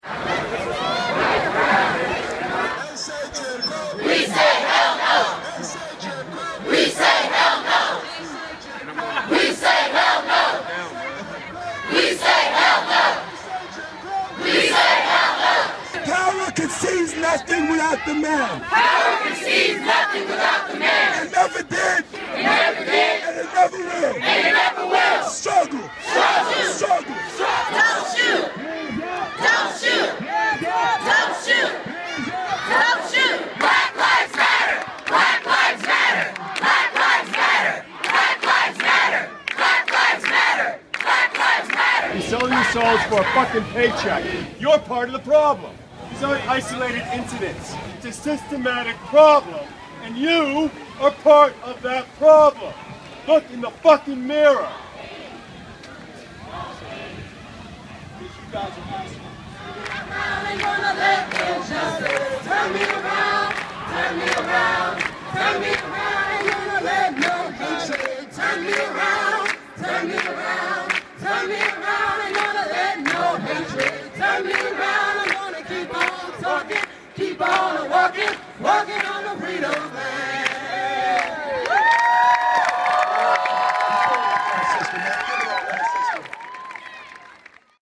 In the hours following the decision not to indict Ferguson police officer Darren Wilson for the fatal shooting of Michael Brown, several hundred protesters gathered at the gates of the North Lawn of the White House. Many protesters were university students who came to vent their anger, gather momentum for future demonstrations, and send a loud message to President Obama.